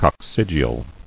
[coc·cyg·e·al]